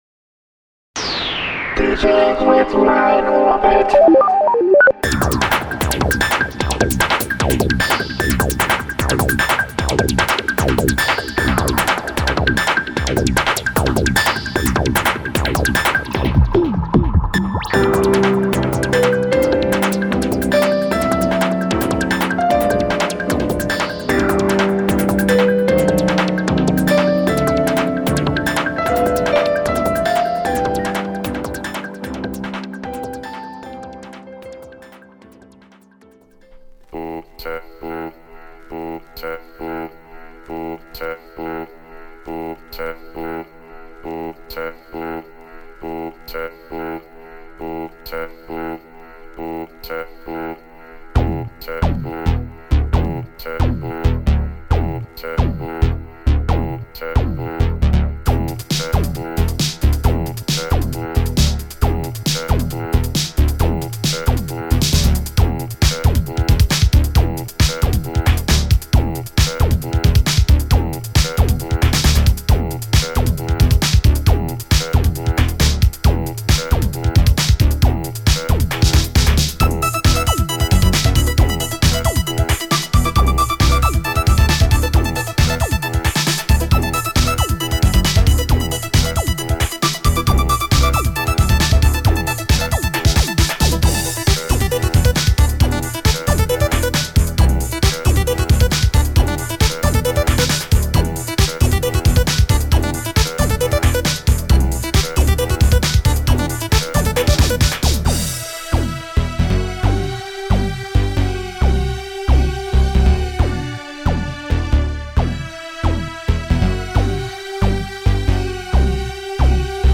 Focused on late 90s – early 2000s Electro Tunes.